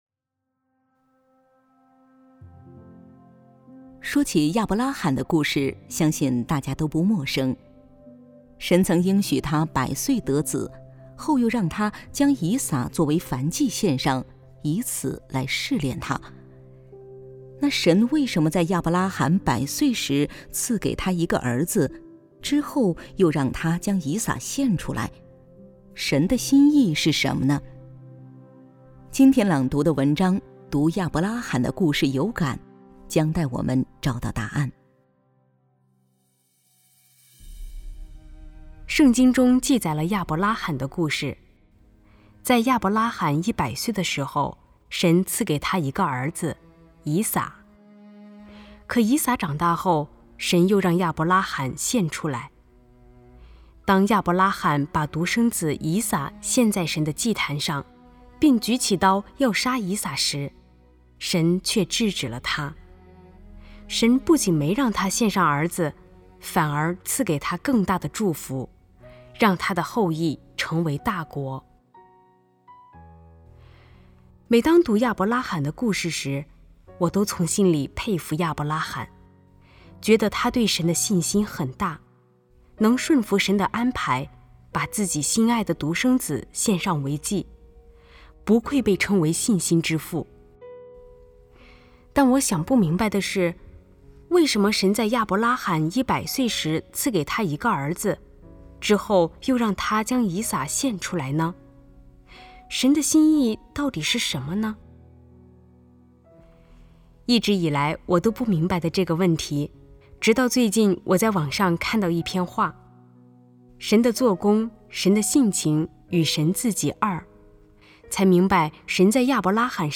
每日靈修：讀亞伯拉罕的故事有感（有聲讀物）